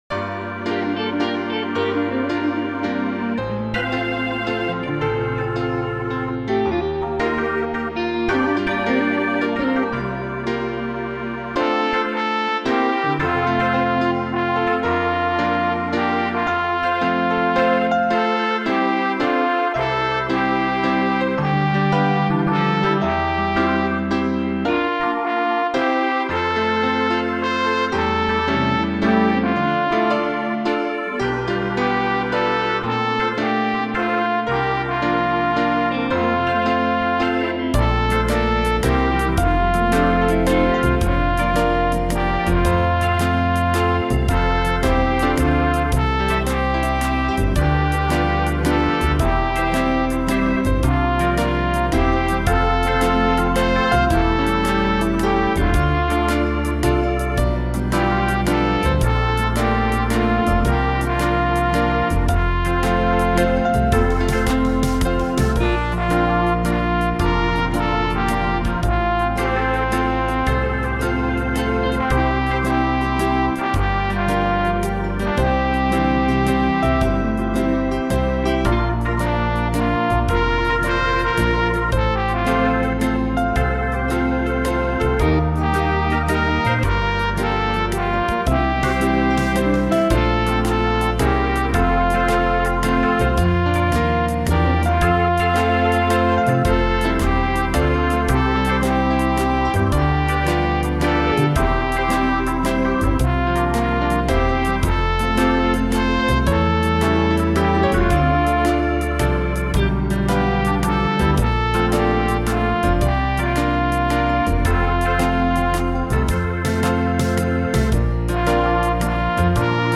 gospel swing song